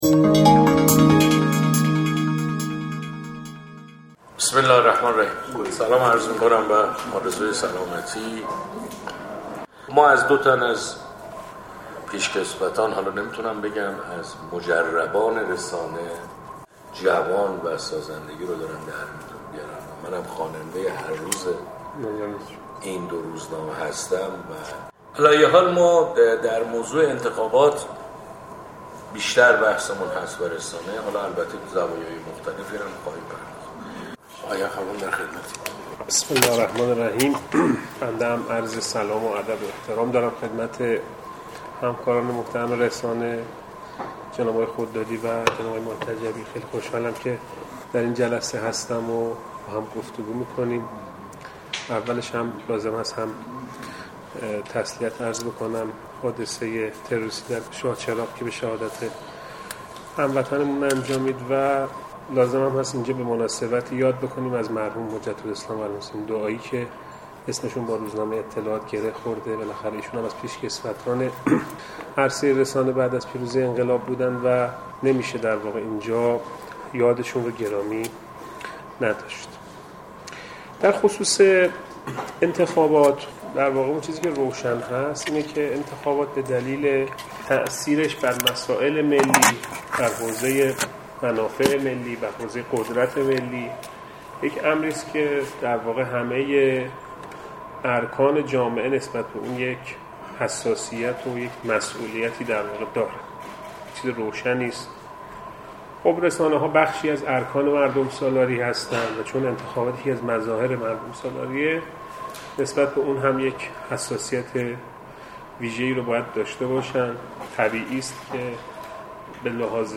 میزگرد